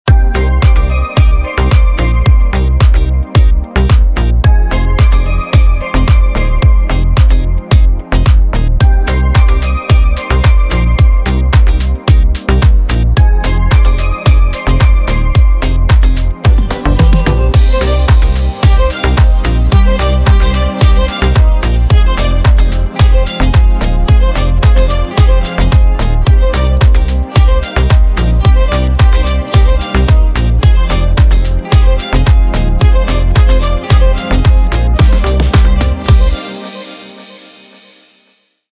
инструментальные , скрипка